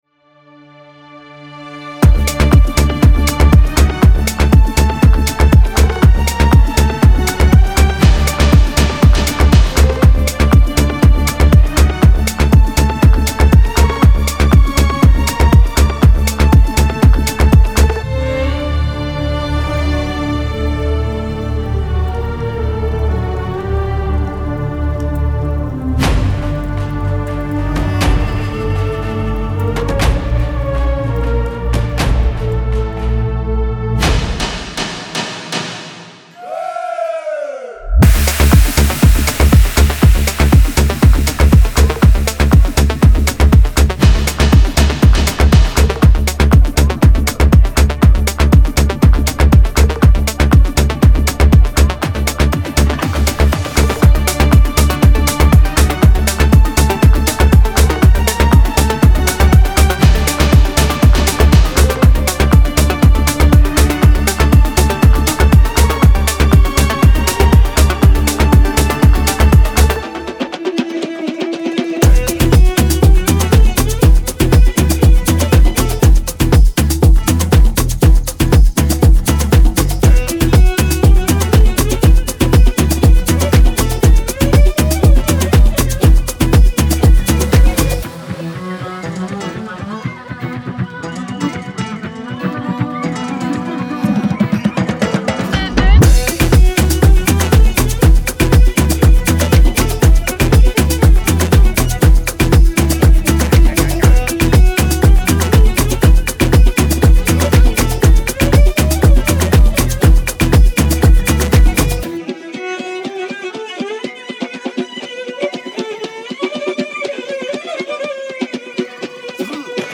◆ 一般的なサンプルパックから抜け出し、アラビックメロディとアフログルーヴを融合させたユニークなサウンドを得られます。
オーディオデモは大きく、圧縮され、均一に聞こえるよう加工されています。
Genre:Afro House
120 BPM